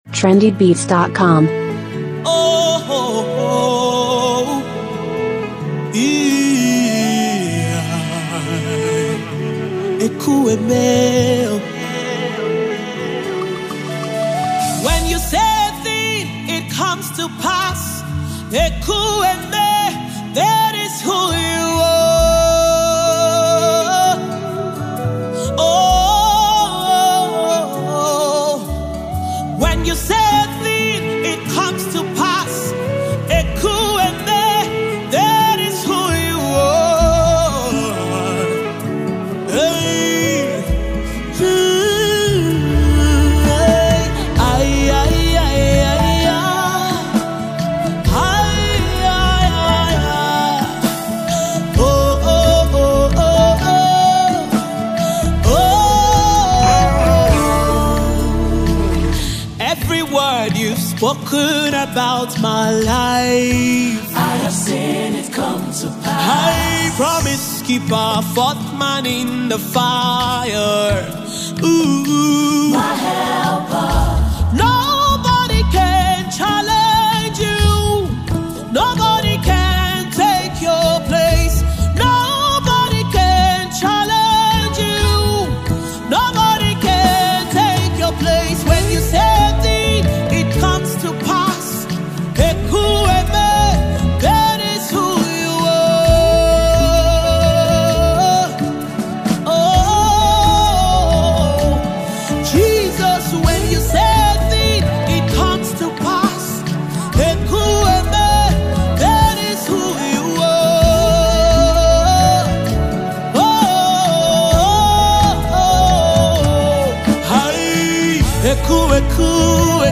Category: Gospel Music